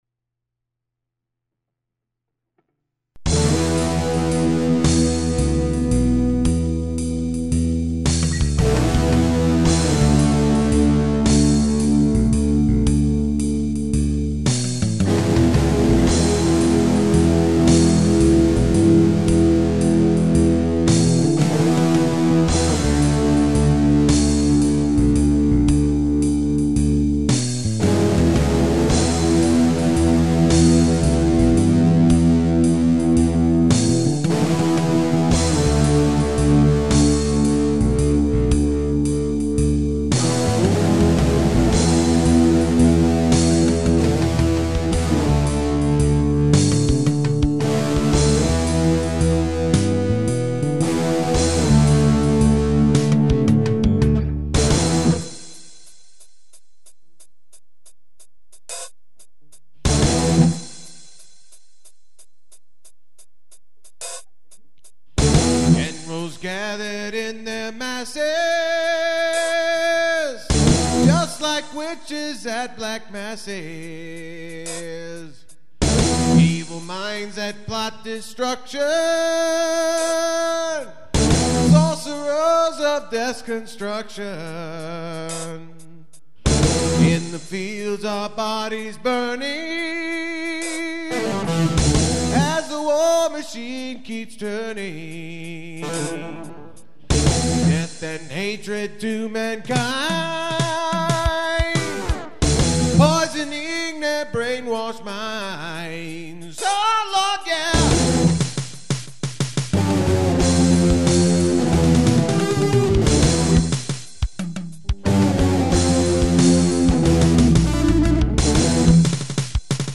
GP5 backing track